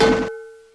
explode3.wav